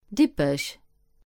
Dippach (Luxembourgish: Dippech [ˈdipəɕ]
Lb-Dippech.ogg.mp3